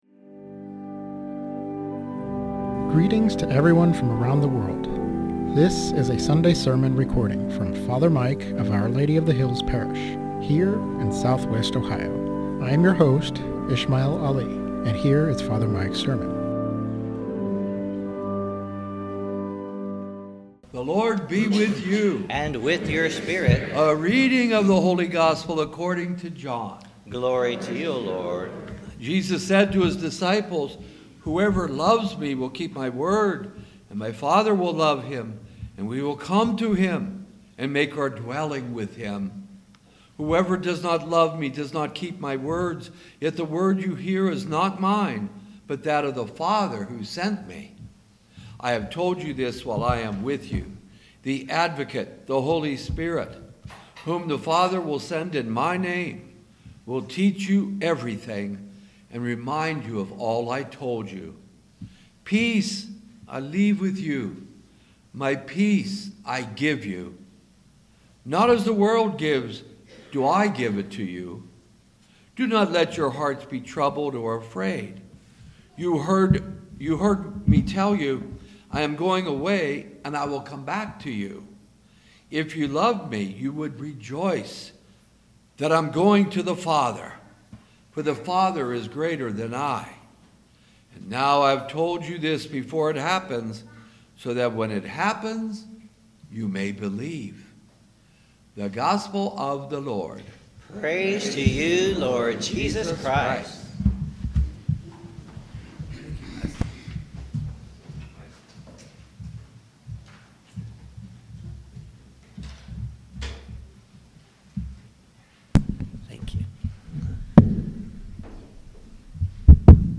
Sermon on John 14: 23-29 - Our Lady of the Hills - Church